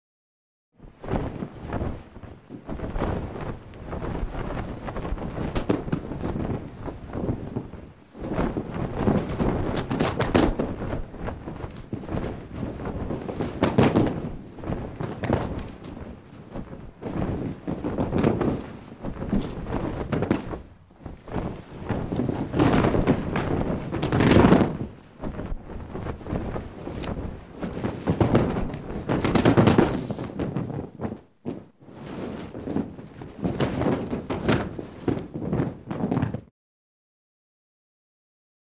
Sailing Ship; Flag Flapping; Flag Flapping In The Wind.